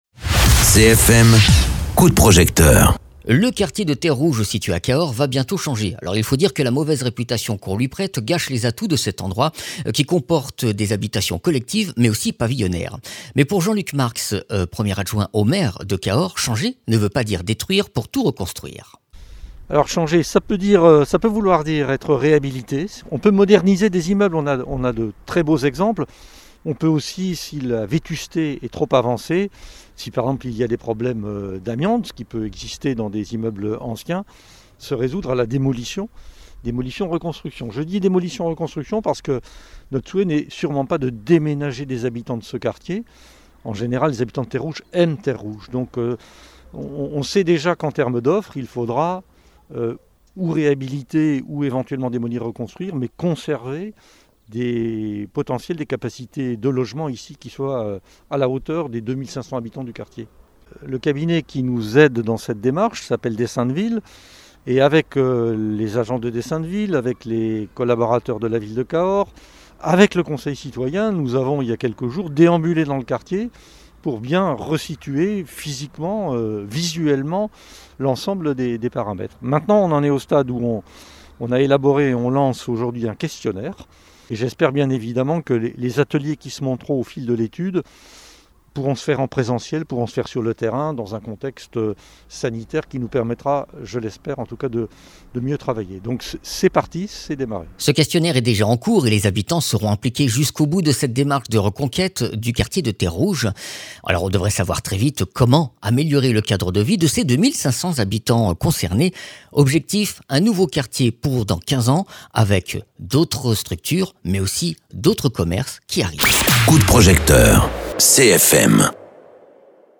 Interviews
Invité(s) : Jean Luc Marx, premier adjoint au maire de Cahors